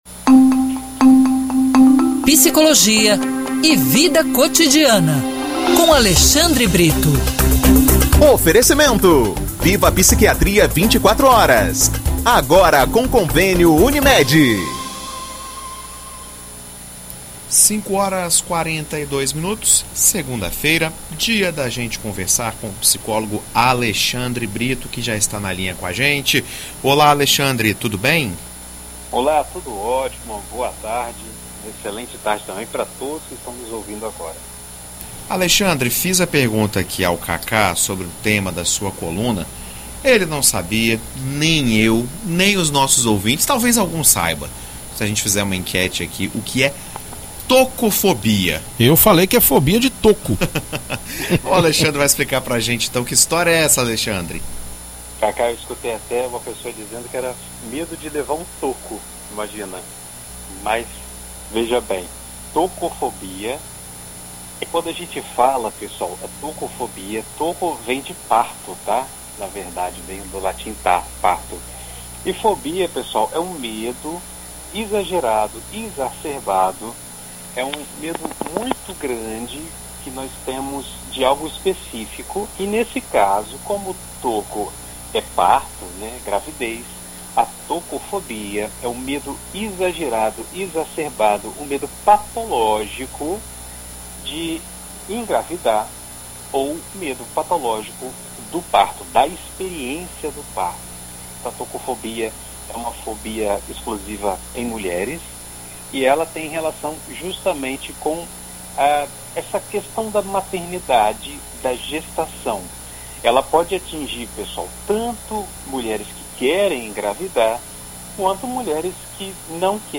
Em entrevista à BandNews FM ES nesta segunda-feira